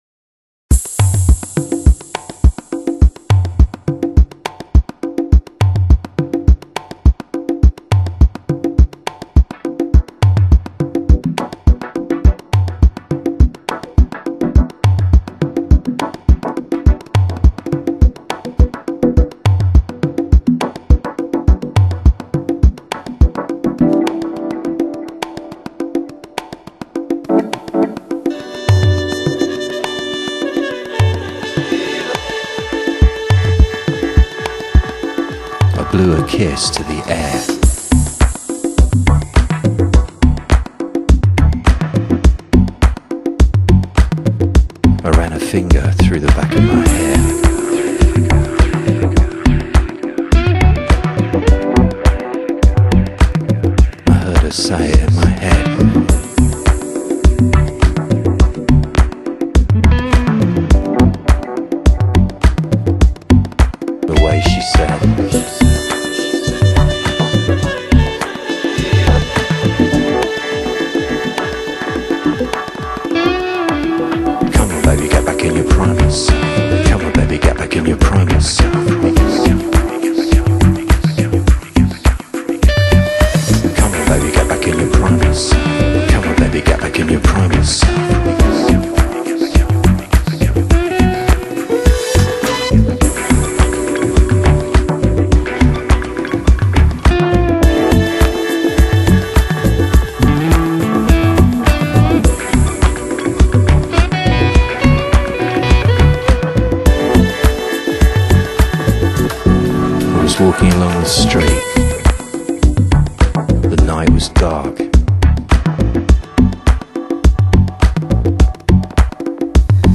Genre: House, Tech House, Deep House